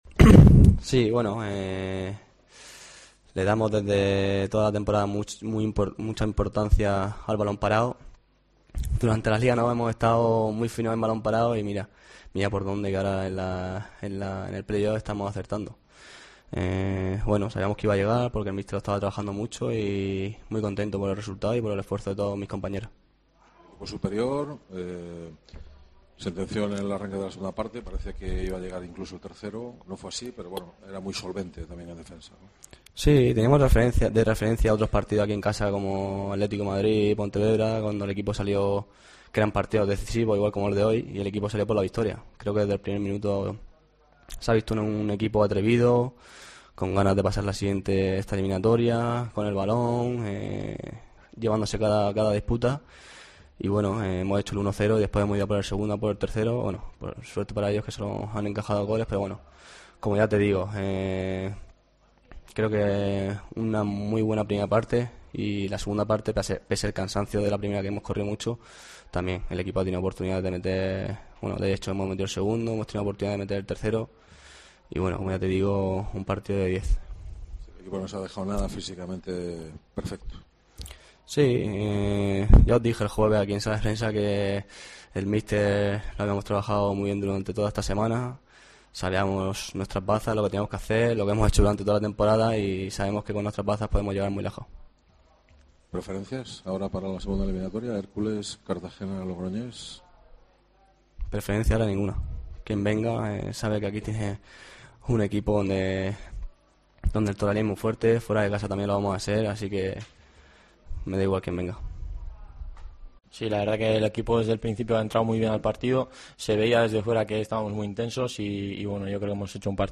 Escucha aquí las declaraciones de los dos jugadores de la Deportiva que marcaron ante los catalanes